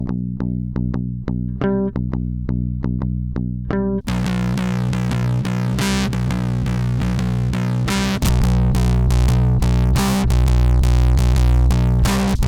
ADAM zřetelně víc komprimuje a hlavně má spoustu středobasů, které člověk nedostane tím onboard eq pryč. Je to prostě ampovější i s vypnutým boxem..